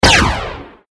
fire_interior.mp3